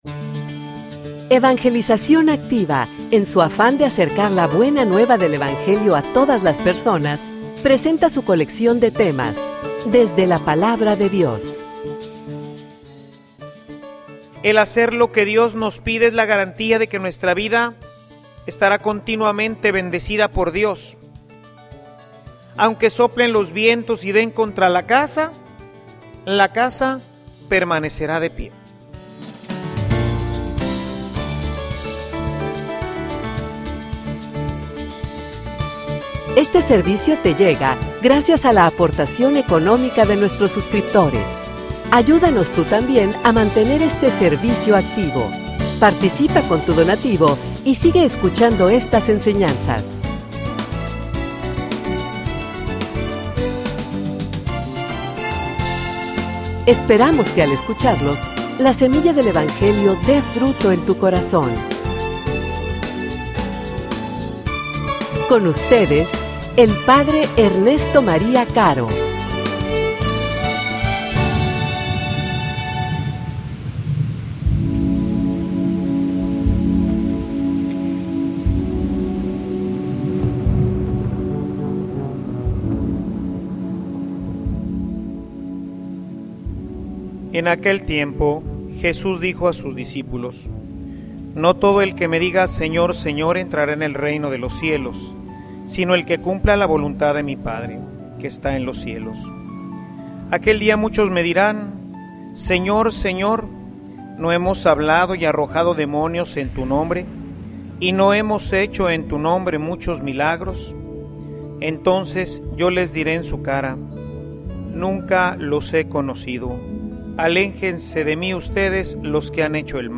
homilia_Pero_un_dia_llegaron_las_lluvias.mp3